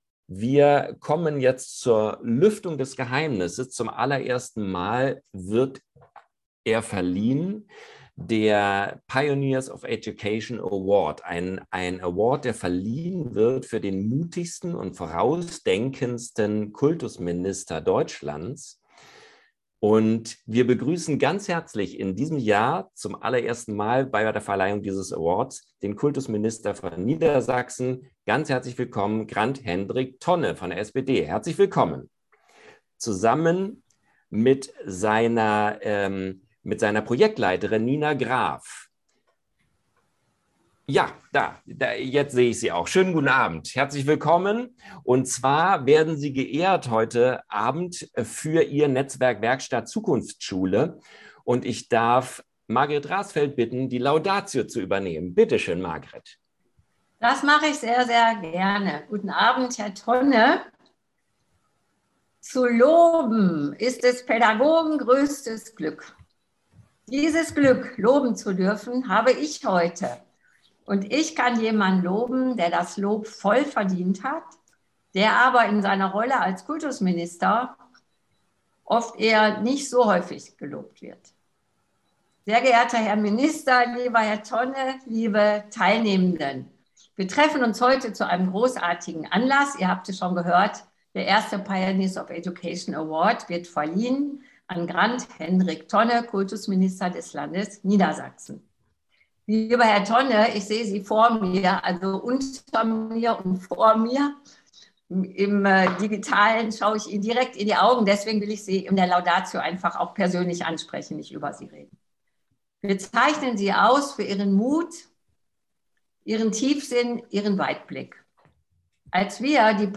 Laudatio & Rede von Minister Tonne anhören
POE22_Award_Reden.mp3